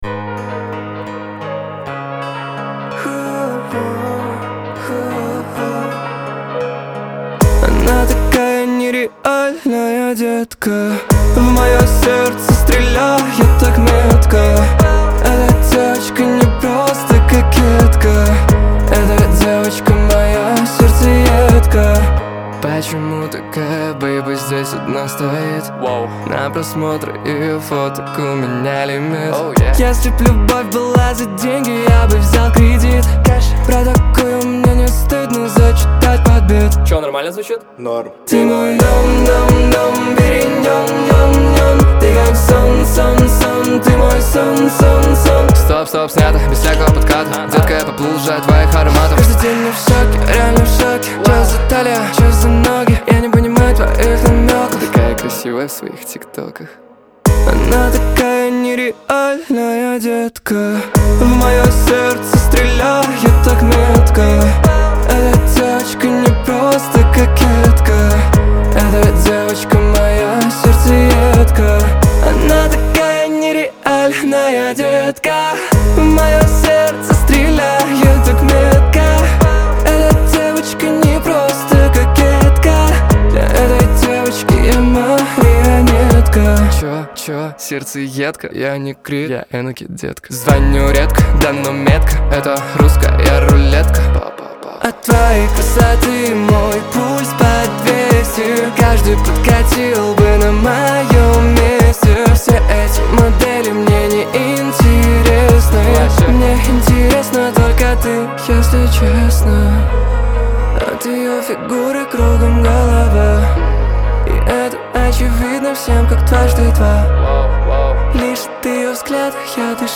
Русская Поп-Музыка